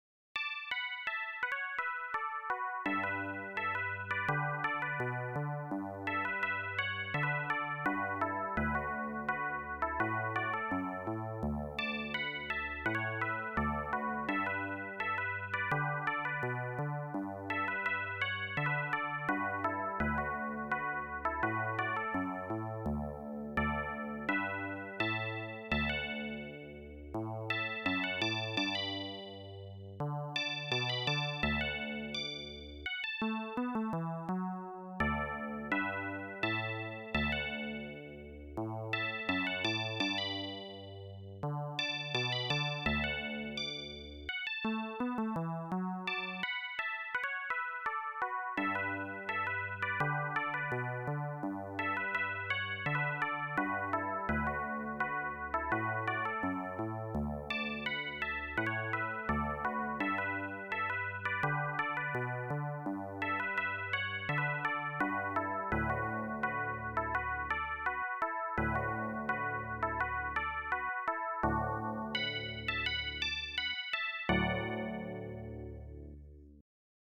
AdLib/Roland Song